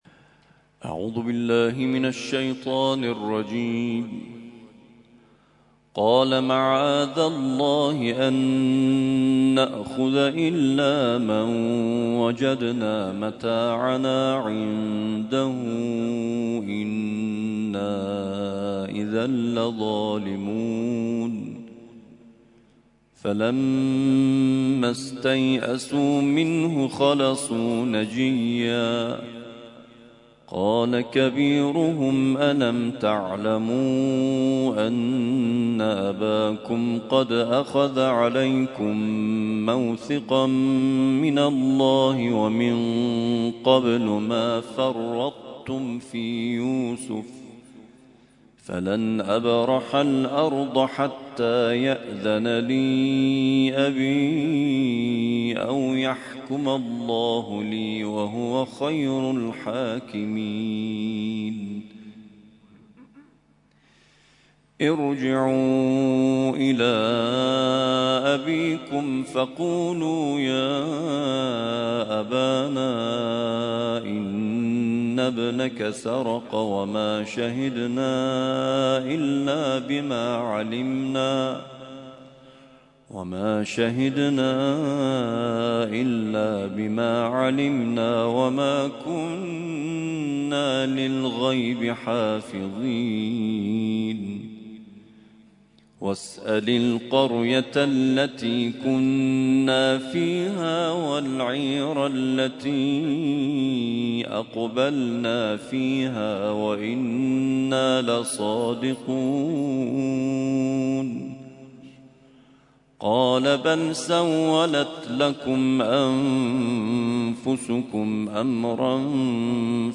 ترتیل خوانی جزء ۱۳ قرآن کریم در سال ۱۳۹۲